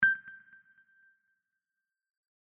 chat_beep.mp3